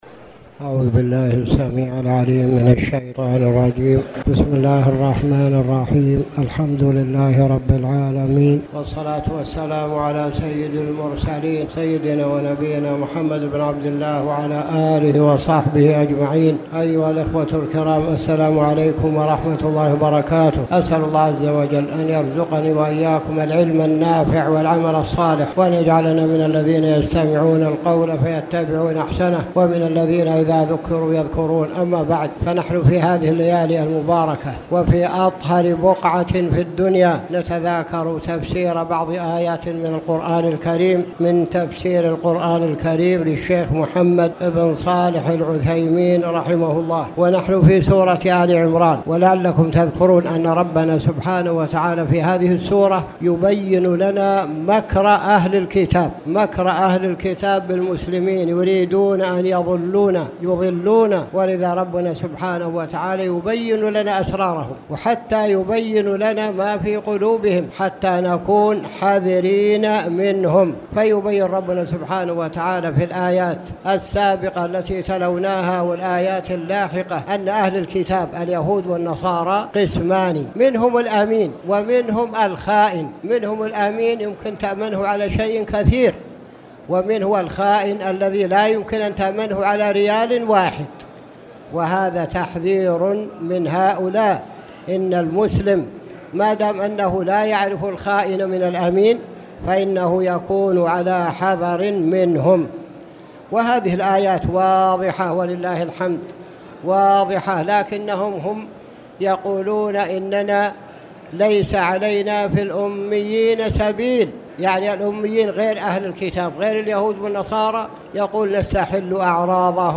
تاريخ النشر ١٥ رمضان ١٤٣٨ هـ المكان: المسجد الحرام الشيخ